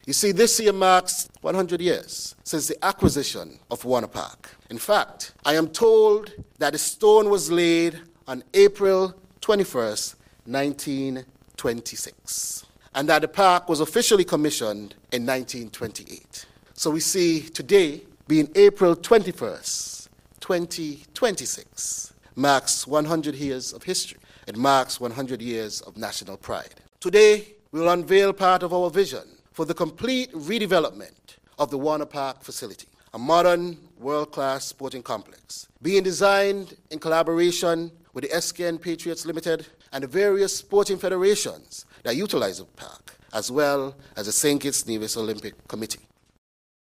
During his quarterly press conference on April 21st, 2026, Federal Minister of Sports and the Creative Economy,  Agriculture, et. al, the Hon. Samal Duggins, spoke about a crucial sporting infrastructure in the federation: